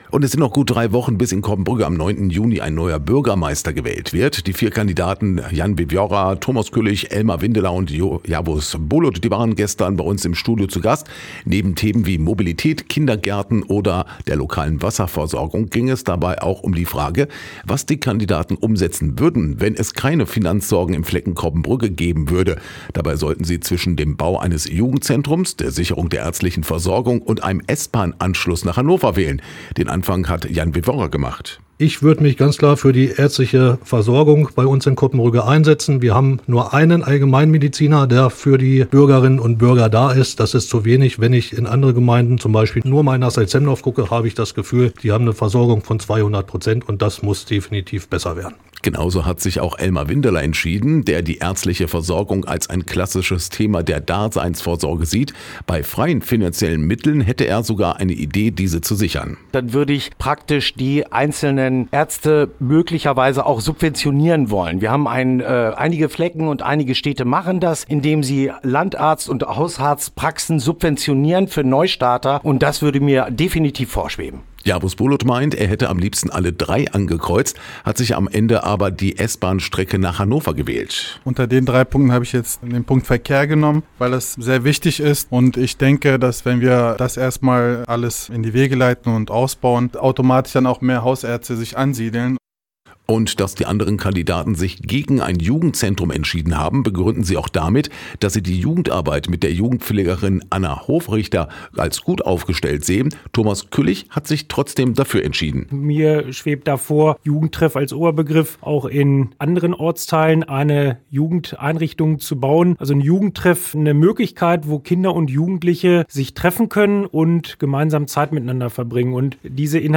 Coppenbrügge: Vier Bürgermeisterkandidaten im radio-aktiv Interview